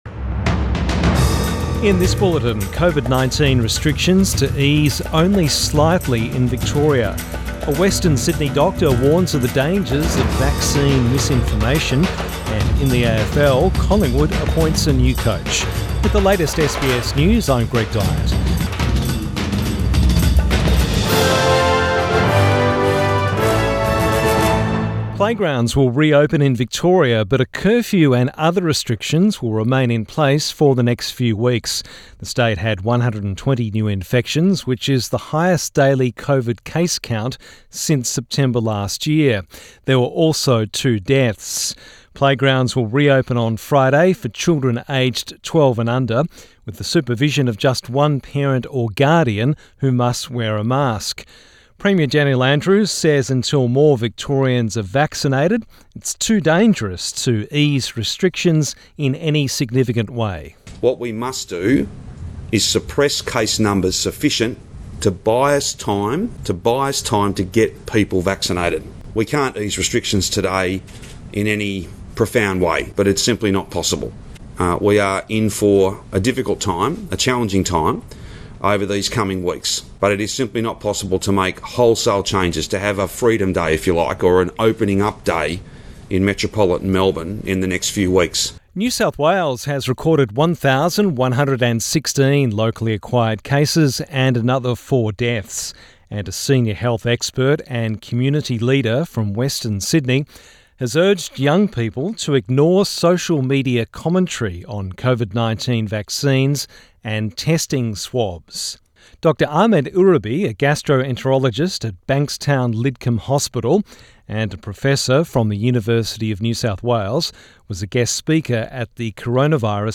PM bulletin 1 September 2021